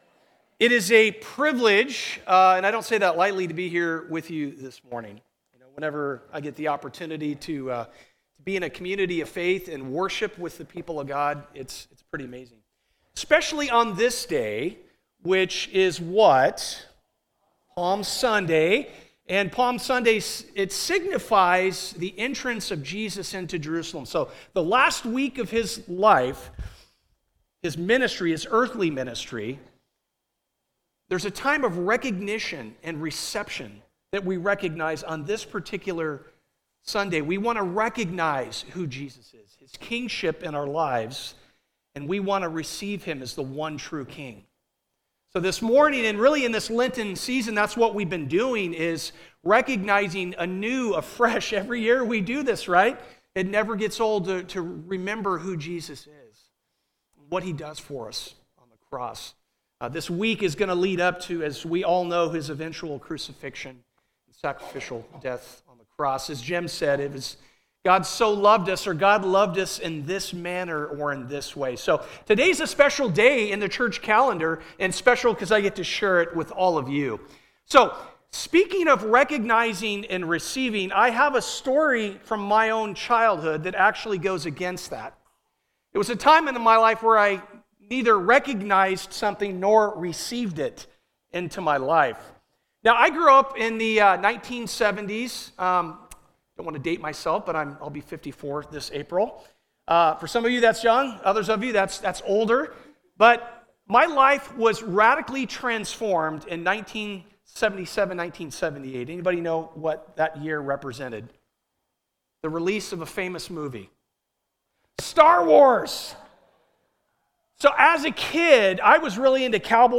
Luke 19:28–44 Service Type: Sunday Can you believe it?